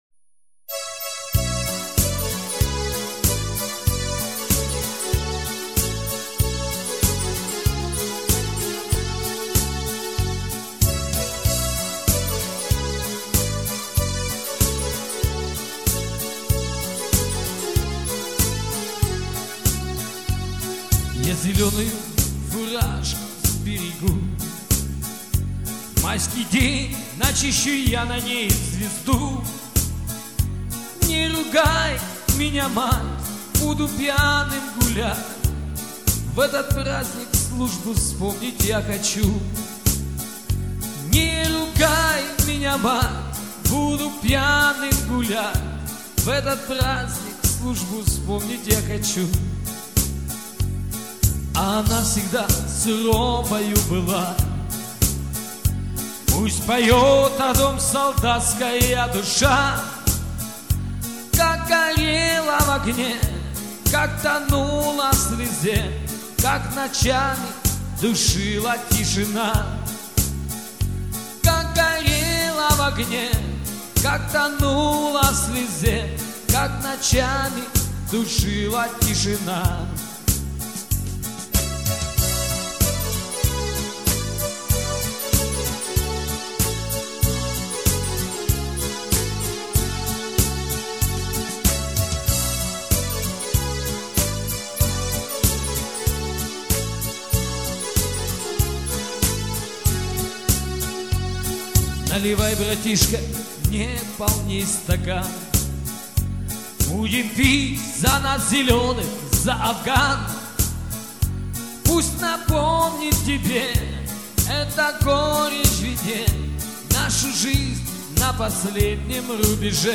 Уличные музыканты - "Афганцы" - Дембельский альбом (Название условное)